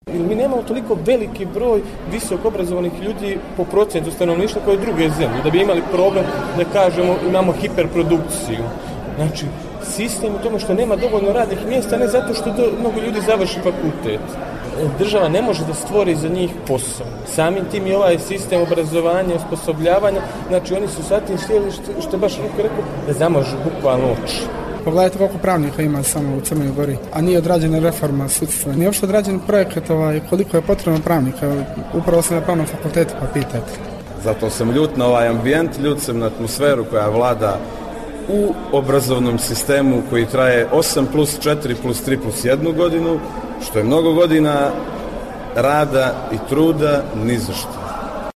Studenti, svjesni problema, ne kriju nezadovoljstvo trenutnim stanjem.
Studenti o perspektivama